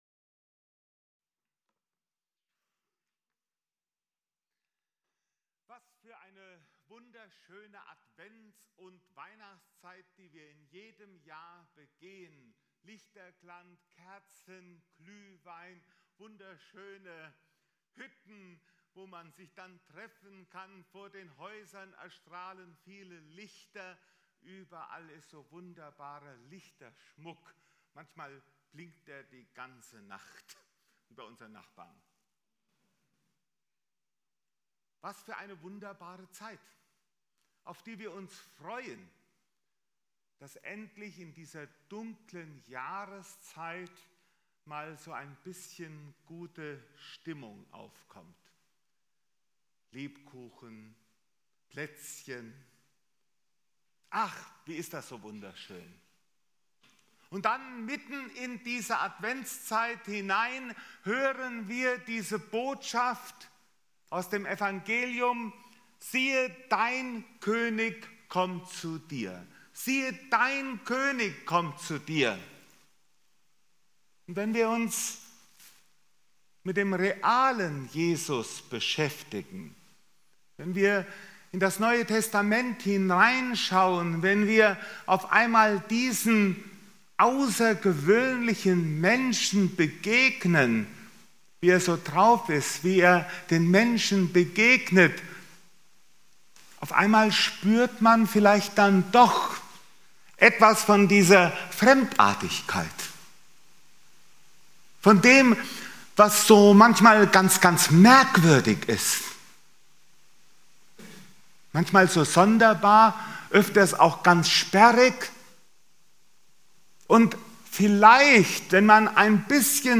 Predigten - FeG Steinbach Podcast